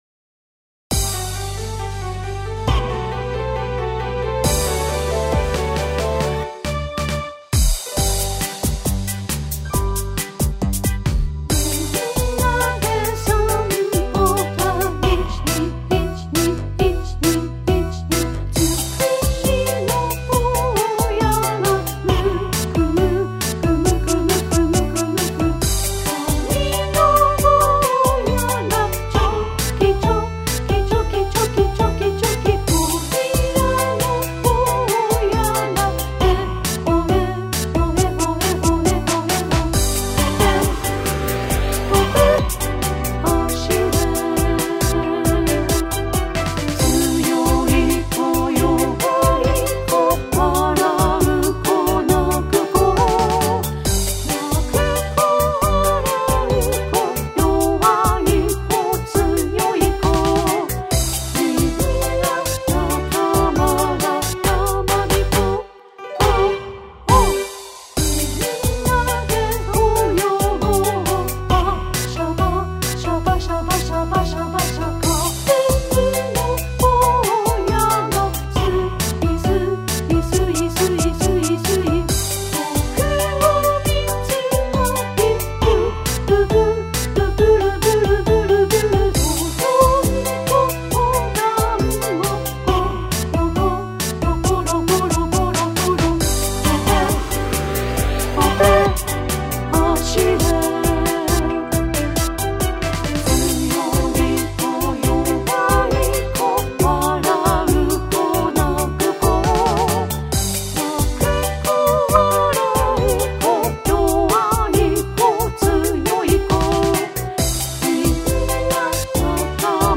やまびこっこ体操（歌入り）.mp3